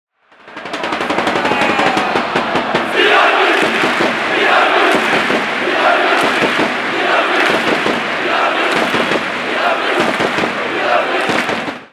Virage Sonore, spécialisé dans l’enregistrement live en haute qualité des chants des tribunes a investi le Virage Brice, la meilleure tribune du championnat, faut il le rappeler, pour capturer et immortaliser les chants qui font du Stadium un des points les plus chauds de l’hexagone ces dernières saisons .